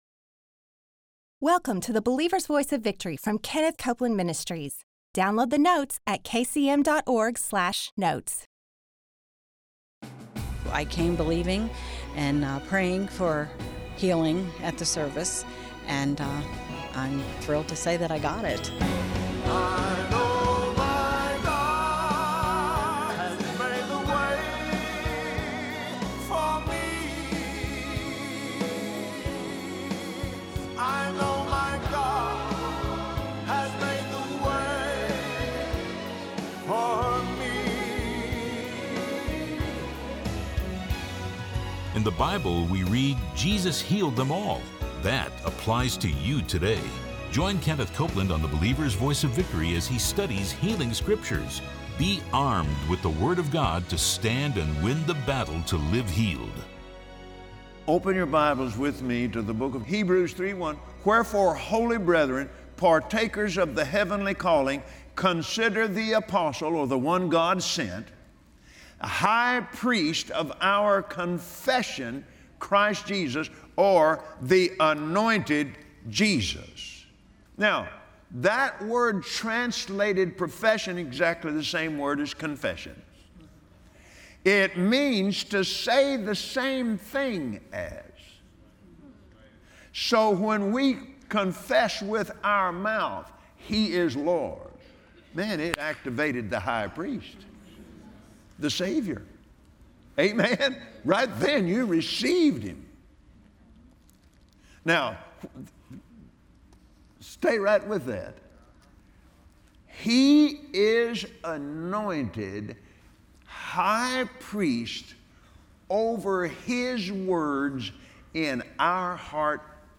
Believers Voice of Victory Audio Broadcast for Wednesday 05/03/2017 Learn to speak the promises of God! Watch Kenneth Copeland on Believer’s Voice of Victory explain how to use Psalms 23 and 91 to declare and receive your divine healing and protection.